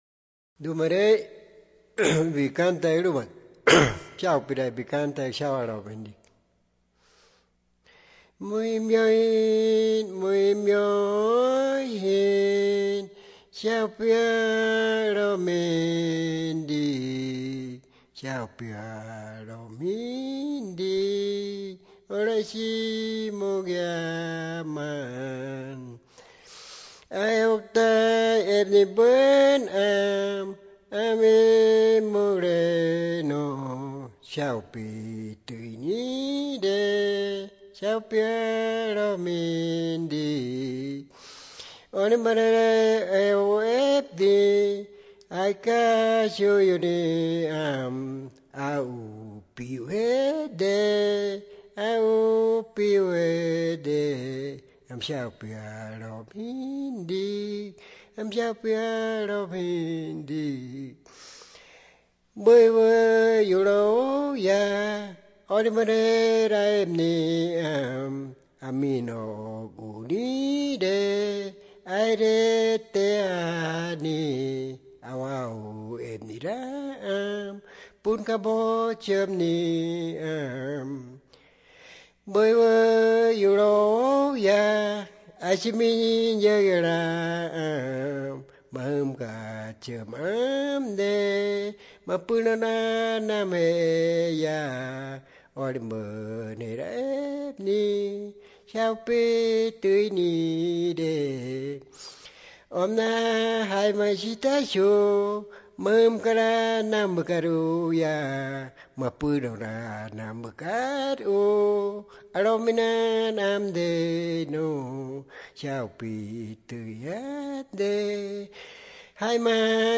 La grabación, transcripción y traducción de la canción se hizo entre marzo y abril de 2001 en Piedra Alta; una posterior grabación en estudio se realizó en Bogotá en 2004
The recording, transcription, and translation of the song took place between March and April 2001 in Piedra Alta; a subsequent studio recording was made in Bogotá in 2004.